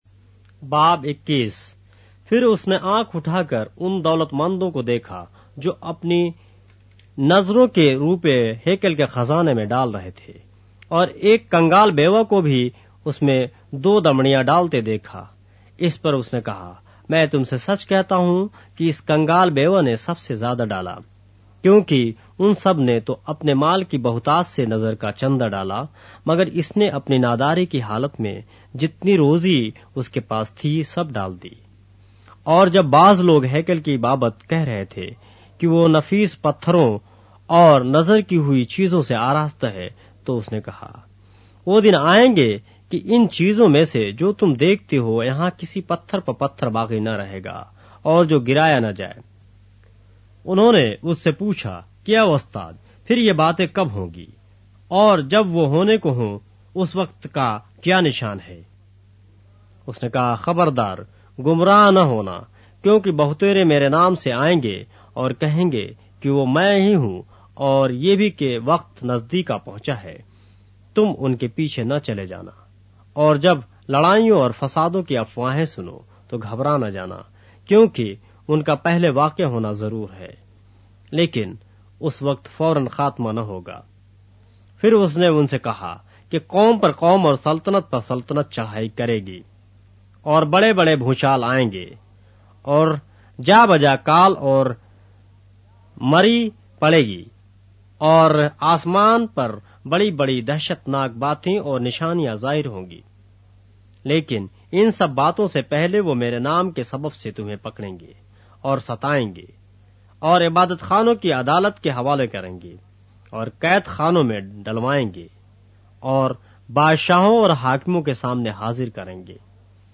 اردو بائبل کے باب - آڈیو روایت کے ساتھ - Luke, chapter 21 of the Holy Bible in Urdu